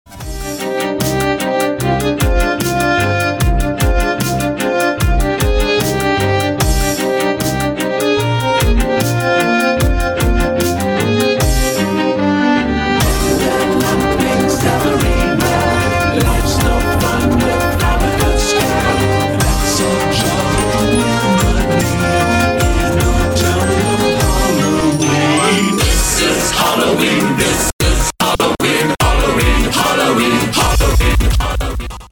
• Качество: 256, Stereo
мужской вокал
громкие
женский вокал
dance
EDM
электронная музыка
скрипка
Dubstep